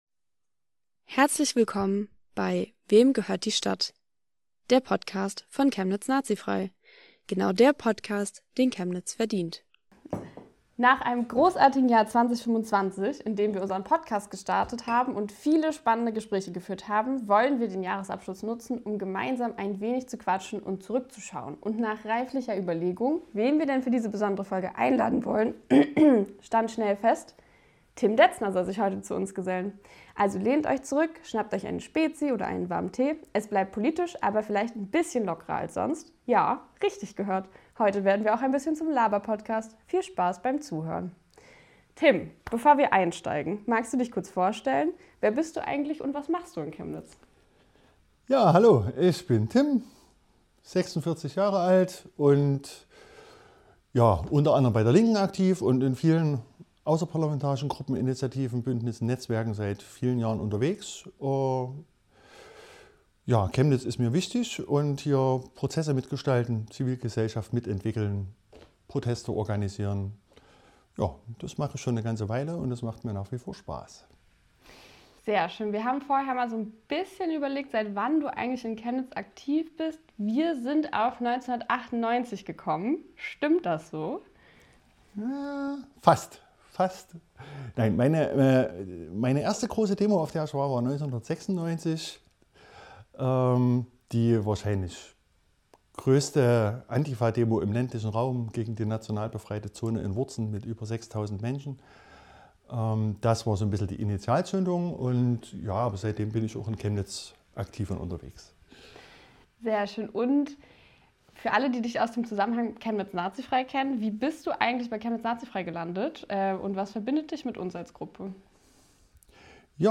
Schnappt euch eine Spezi oder einen warmen Tee – heute wird es politisch, persönlich und ein bisschen zum Laberpodcast. Ein Gespräch über Durchhalten, Zweifel, Motivation – und warum Aufhören keine Option ist.